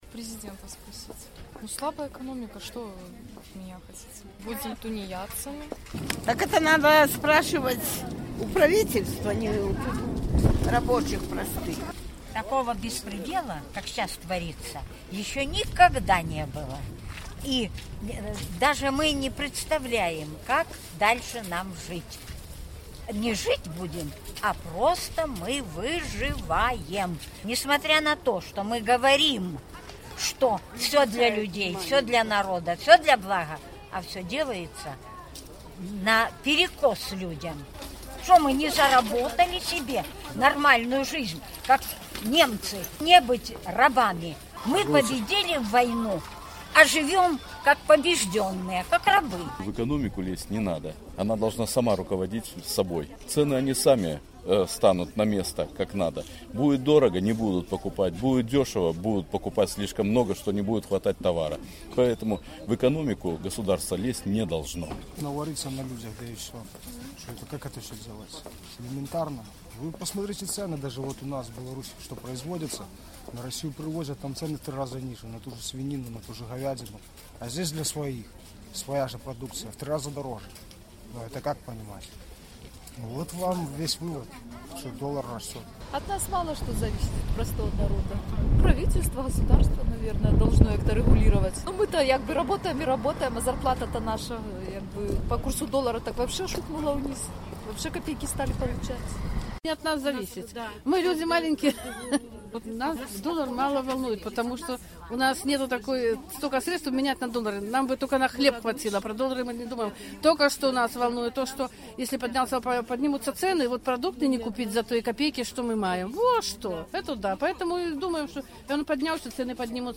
Нас даляр не хвалюе — нам абы на хлеб хапіла, — vox populi ў Баранавічах